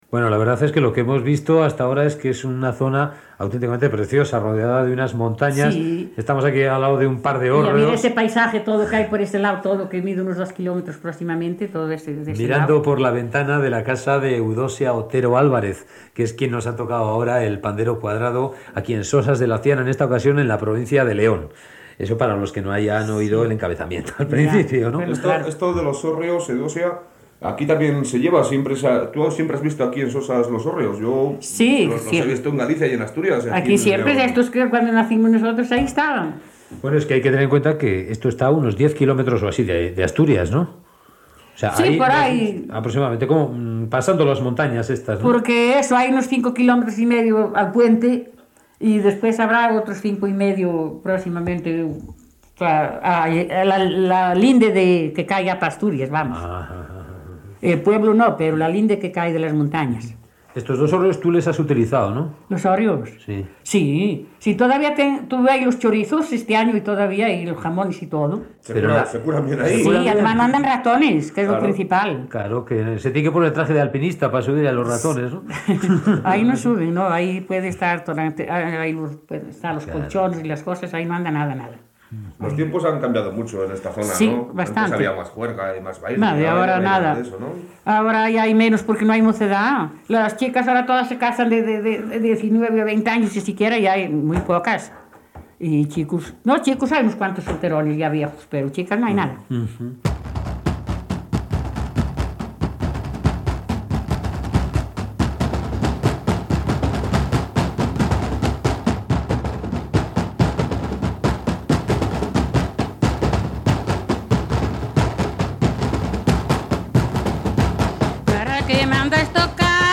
feta a Sosas de Laciana (Lleó), Inclou algun cant popular acompanyat del "pandero"
Entreteniment